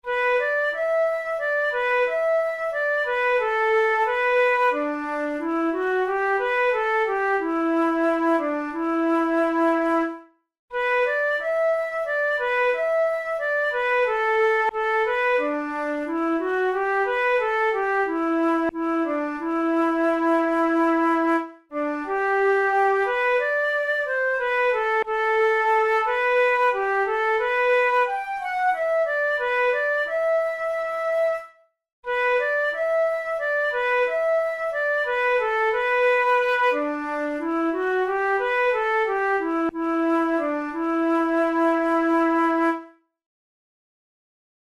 Traditional Irish march